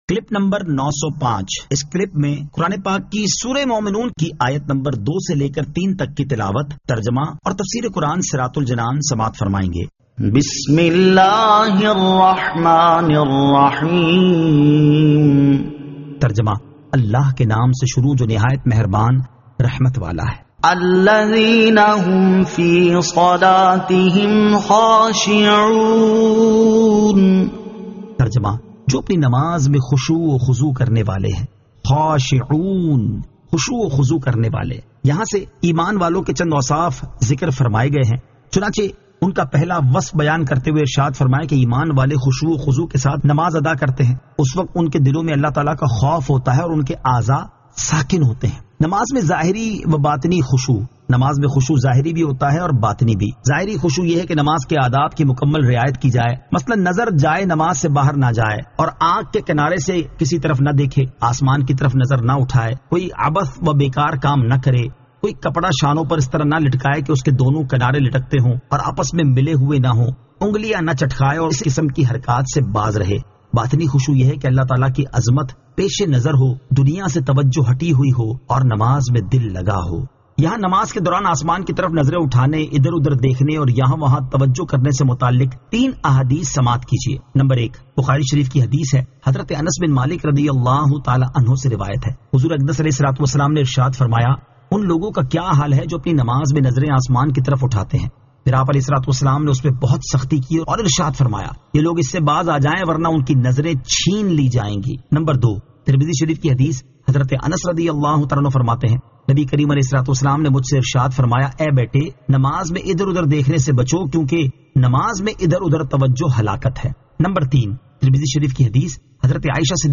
Surah Al-Mu'minun 02 To 03 Tilawat , Tarjama , Tafseer